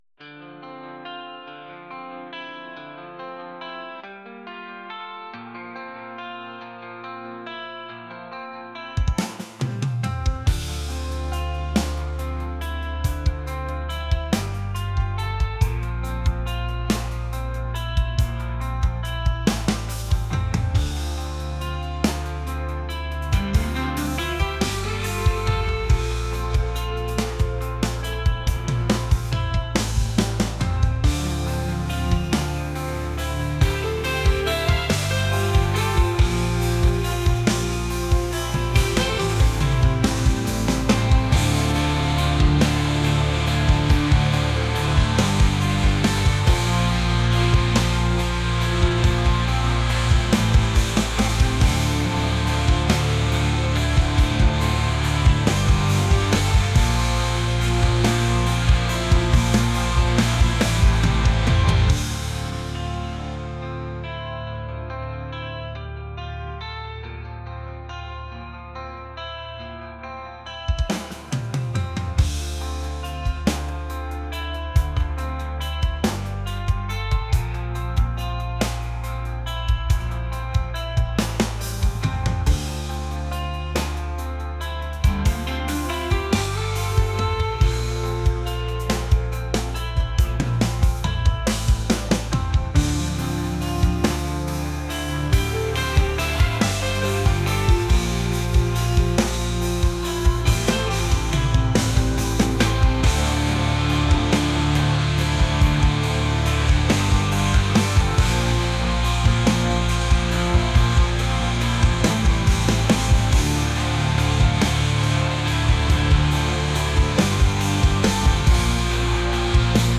indie | rock | ambient